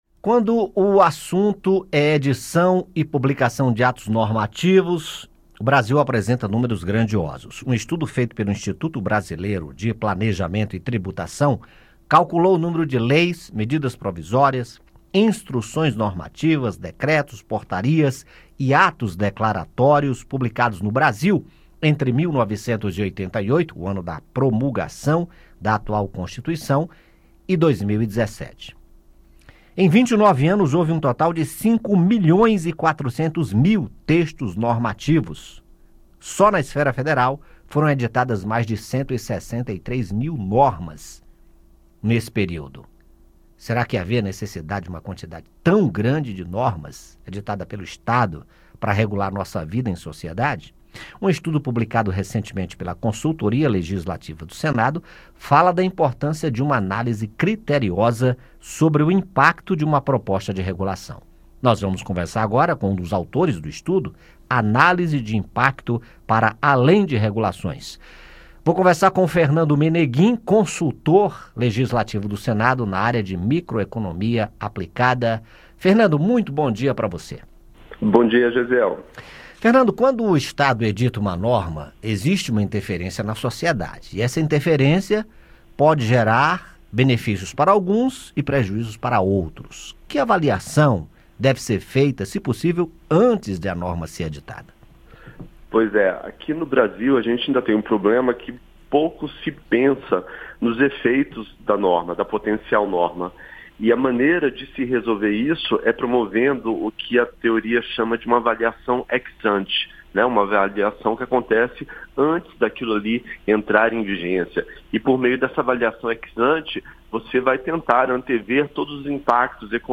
Entrevista: mais de 5 milhões de normas legislativas no Brasil.